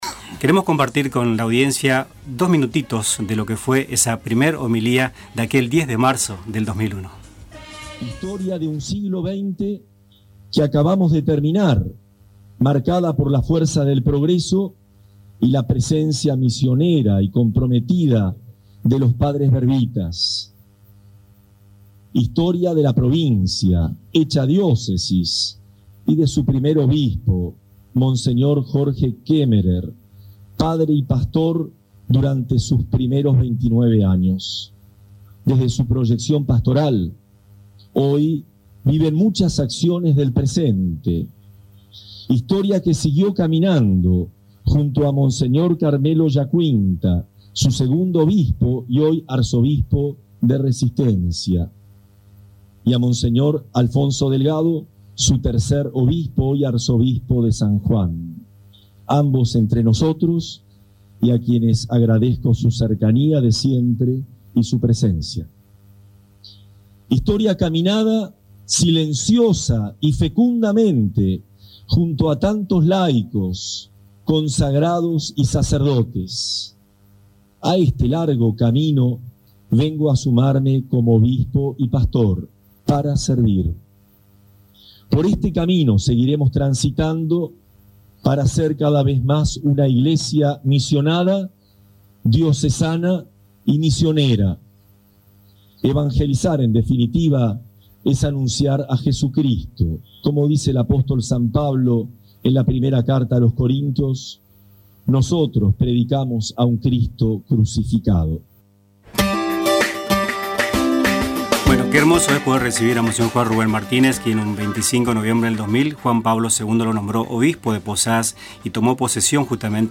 En una entrevista con Radio Tupambaé, el obispo recordó su llegada a Misiones en 2001 y subrayó la importancia de la comunión eclesial, la evangelización de la cultura y la atención a los más pobres.